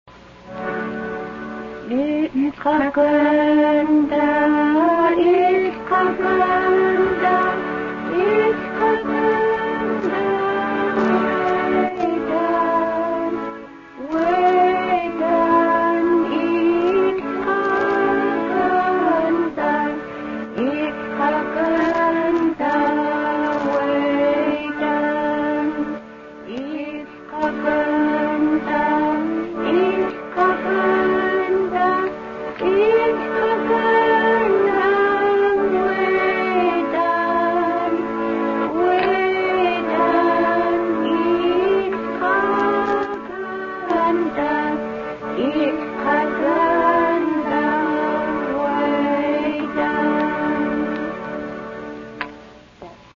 Here are six short gospel songs sung in Haida.
gospel-song-05.mp3